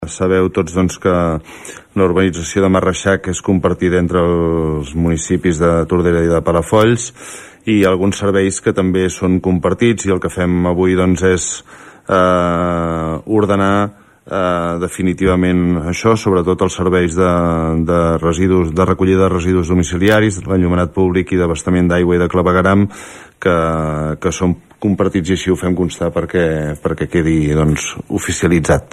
Amb aquest conveni, s’acorda que siguin compartits els serveis de recollida de residus, enllumenat i d’abastament d’aigua i clavegueram. Ho explicava el regidor d’urbanisme, Josep Llorens.